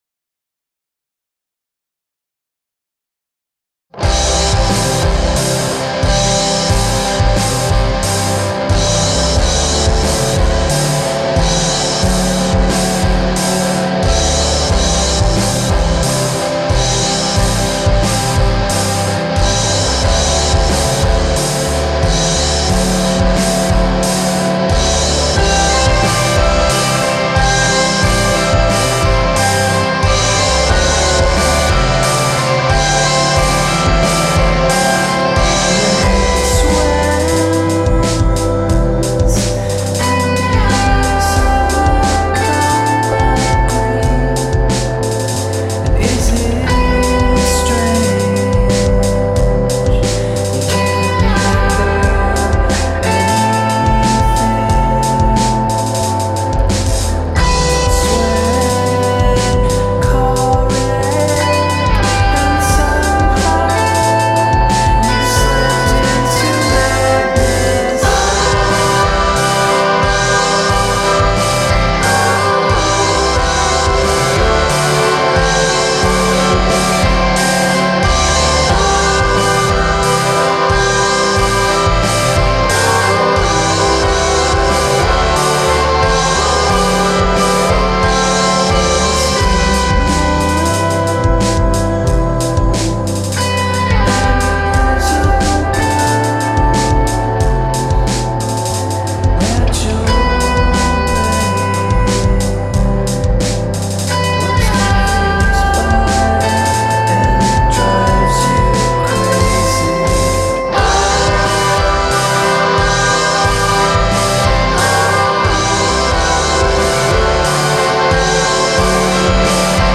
shoegaze vibe